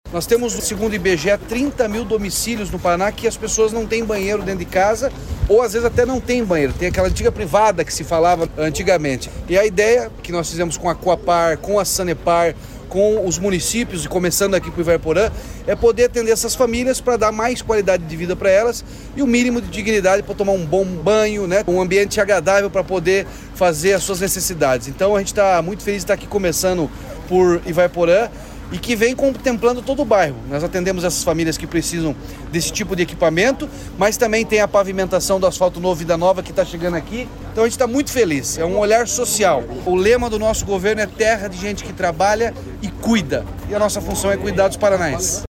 Sonora do governador Ratinho Junior sobre a entrega dos primeiros módulos sanitários do programa Banheiro em Casa em Ivaiporã